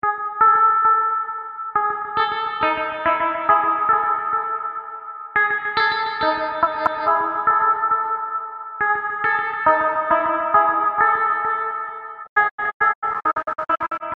BC金属环
描述：一个金属环
Tag: 冲击 金属